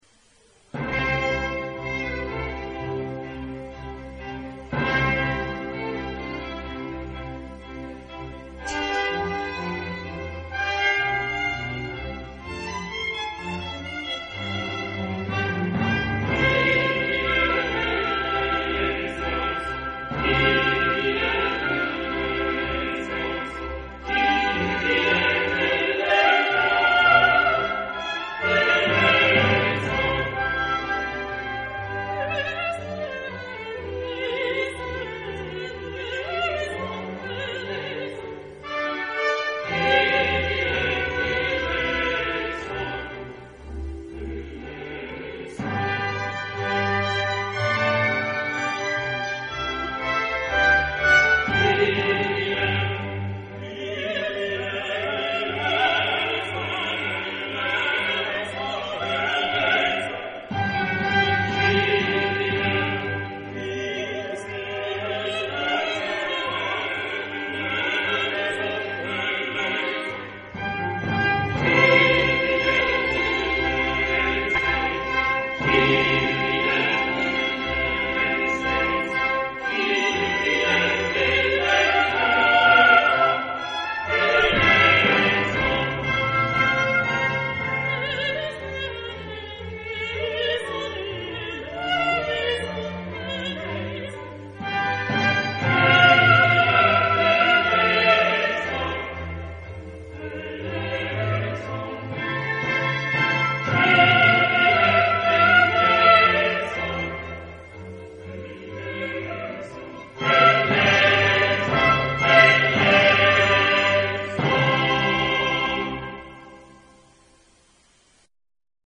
Genre-Style-Form: Sacred ; Classic ; Mass
Type of Choir: SATB  (4 mixed voices )
Soloist(s): SATB  (4 soloist(s))
Instruments: Violin (2) ; Basso continuo ; Trumpet (2) ; Timpani (2) ; Organ (1)
Tonality: C major